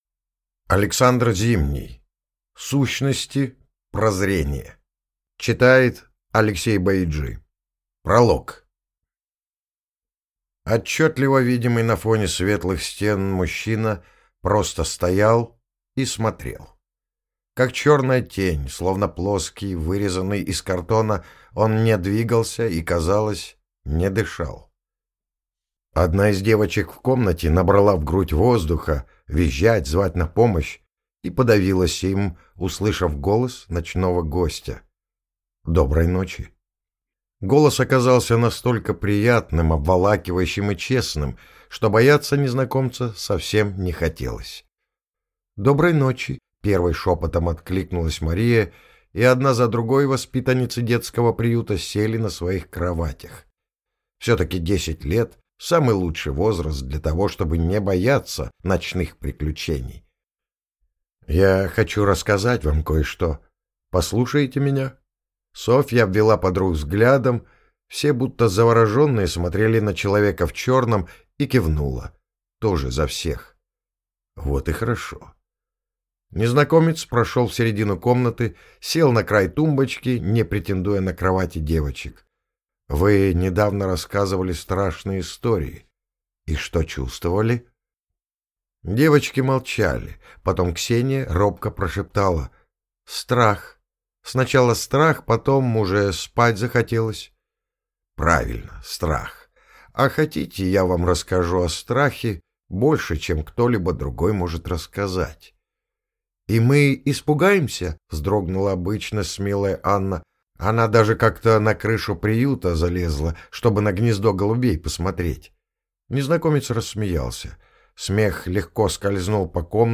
Аудиокнига Сущности. Прозрение | Библиотека аудиокниг